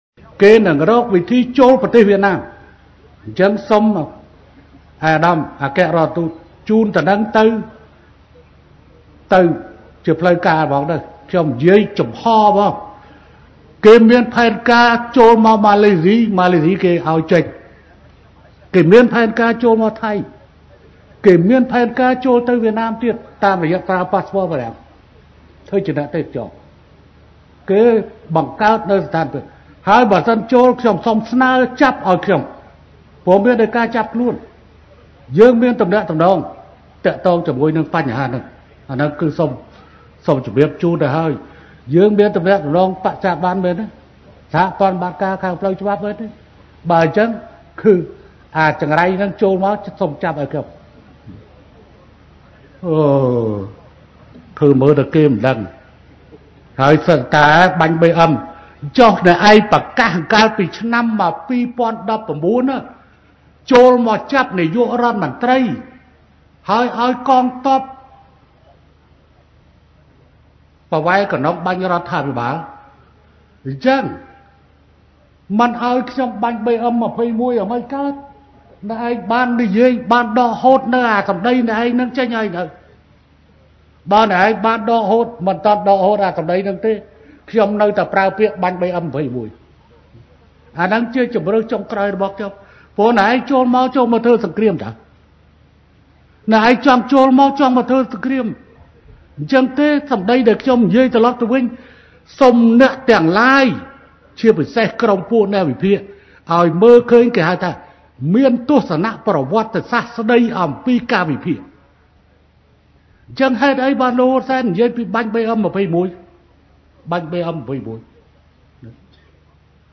សម្តេចតេជោ បានបញ្ជាក់ដូច្នេះថា៖ «គេមានផែនការចូលមកម៉ាឡេស៊ី ម៉ាឡេស៊ីគេឱ្យចេញ គេមានផែនការចូលមកថៃ គេមានផែនការចូលមកវៀតណាមទៀត តាមរយៈការប្រើប៉ាស្ព័របារាំងធ្វើជាអ្នកទេសចរណ៍ ហើយបើសិនចូល ខ្ញុំស្នើចាប់ឱ្យខ្ញុំ ព្រោះមានដីកាចាប់ខ្លួន»ការប្រកាសរបស់ សម្តេចតេជោ ហ៊ុន សែន បានធ្វើឡើង ក្នុងឱកាសដែលសម្តេចថ្លែងសុន្ទរកថា ក្នុងពិធីបើកការដ្ឋានសាងសង់ផ្លូវល្បឿនលឿនភ្នំពេញ-បាវិត ដែលជាផ្លូវល្បឿនលឿនទី២ នៅកម្ពុជា នាព្រឹកថ្ងៃទី០៧ ខែមិថុនា ឆ្នាំ២០២៣នេះ។
ខាងក្រោមនេះជាប្រសាសន៍សម្ដេចតេជោ ហ៊ុន សែន៖